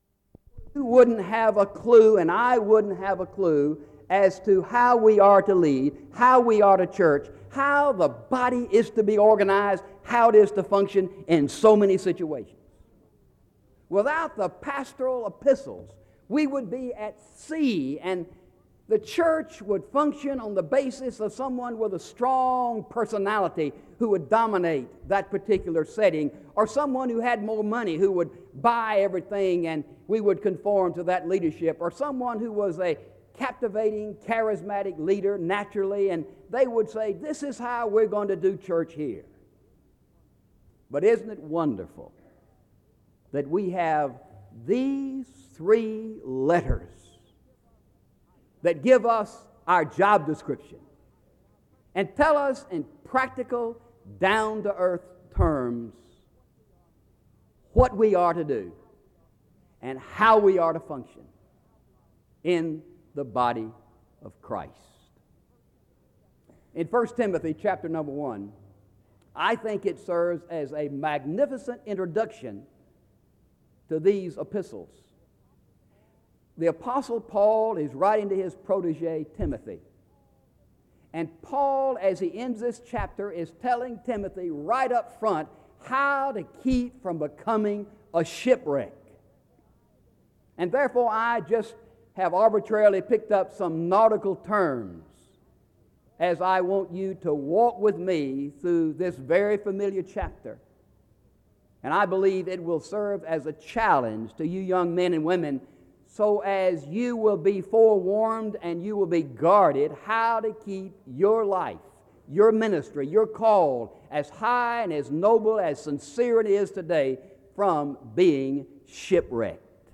SEBTS Commencement
Location Wake Forest (N.C.)